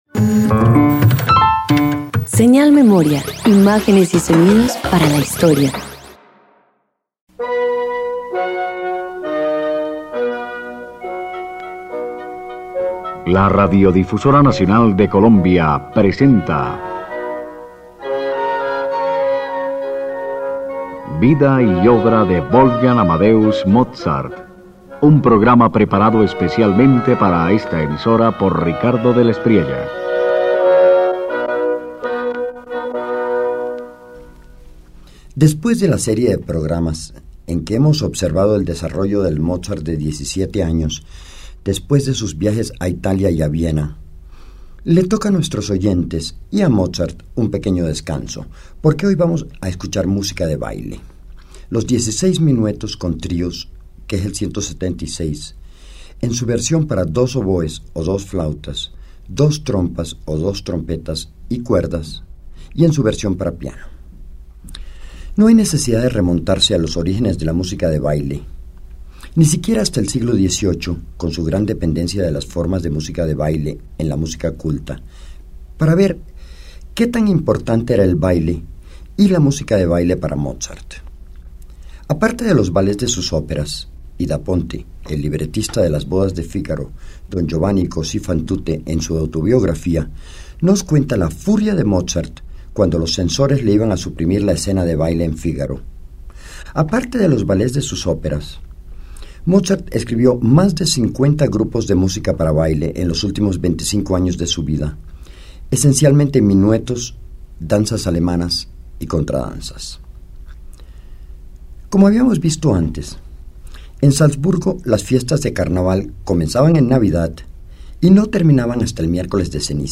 Mozart compone dieciséis minuetos para el Carnaval de Salzburgo de 1774: piezas de baile llenas de alegría, variedad rítmica y refinamiento orquestal, donde incluso la música más ligera refleja su impulso sinfónico y creatividad desbordante.
086 Dieciséis minuetos con tríos_1.mp3